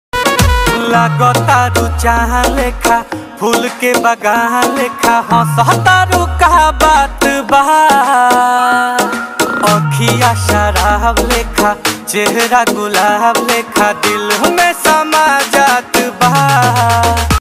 Enjoy this trending Bhojpuri ringtone on your phone.